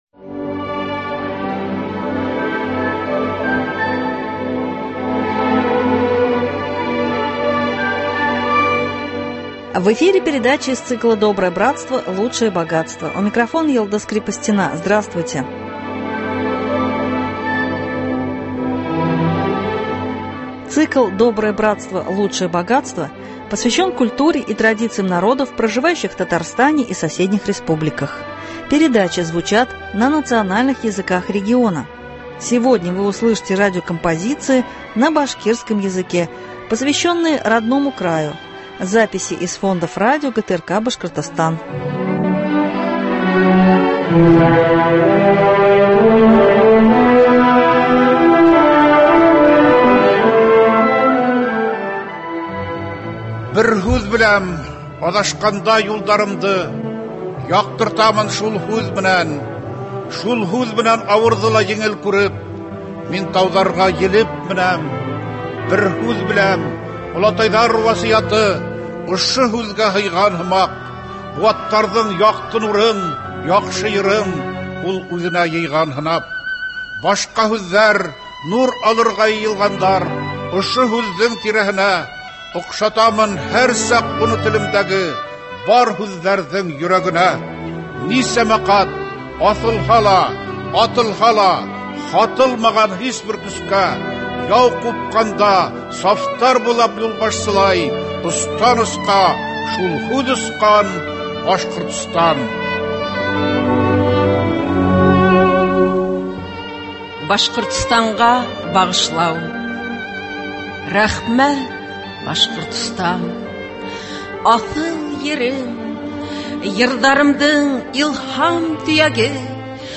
Литературно - музыкальная программа на башкирском языке.